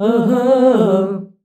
AHAAH C.wav